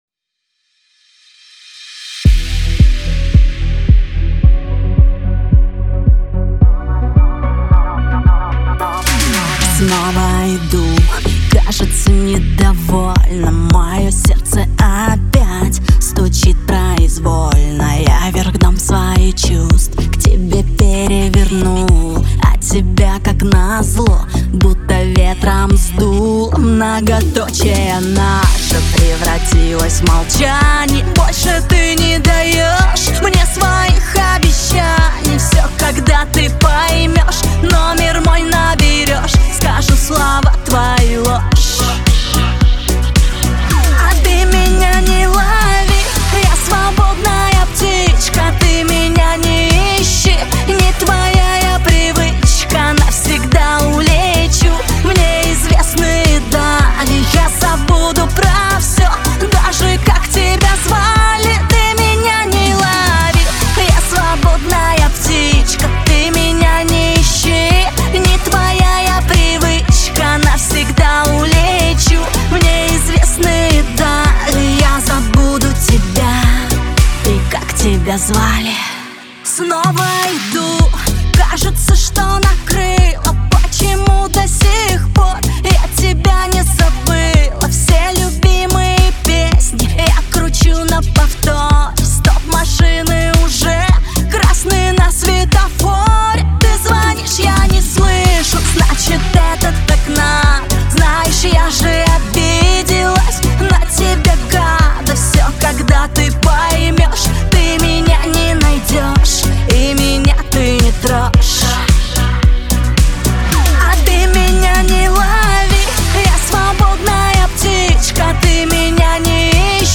Шансон
Лирика